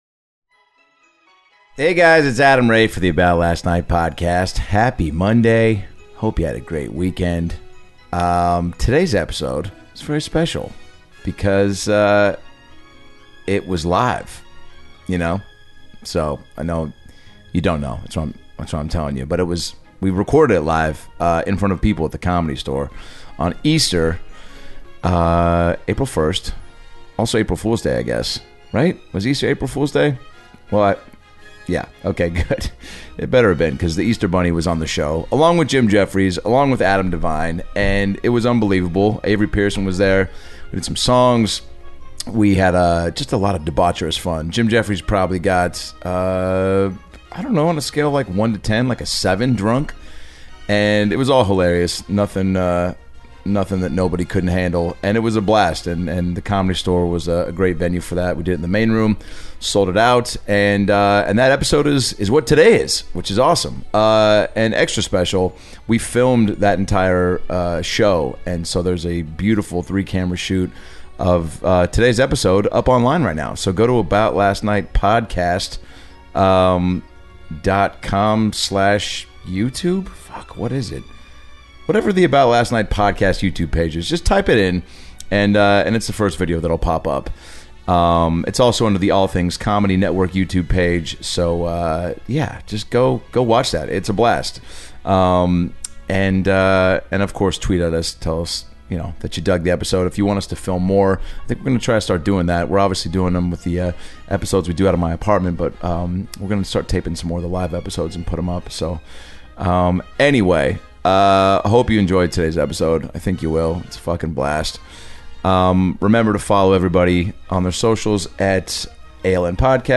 ALN - LIVE from The Comedy Store!
LIVE from The Comedy Store in Los Angeles, CA, the About Last Night podcast made Easter 2018 one you’ll never forget! Joined by Jim Jefferies, Adam Devine & the Easter Bunny, this episode has it all!